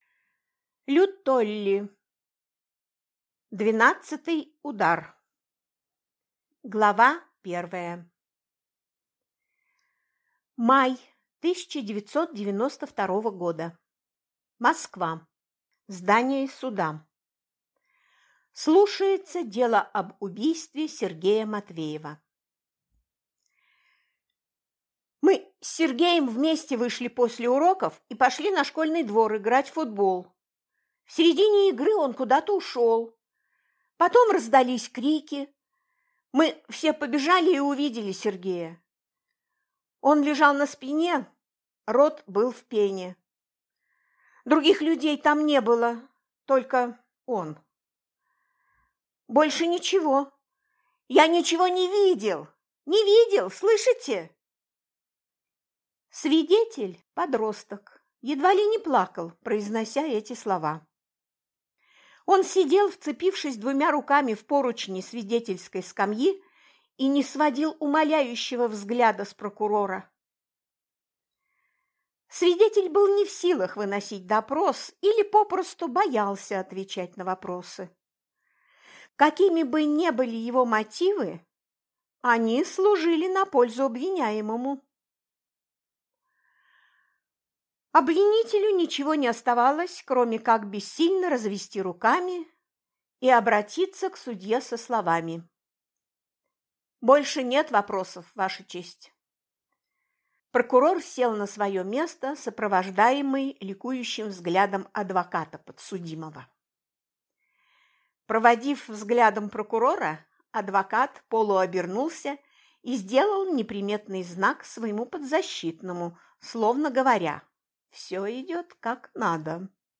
Аудиокнига 12-й удар | Библиотека аудиокниг
Прослушать и бесплатно скачать фрагмент аудиокниги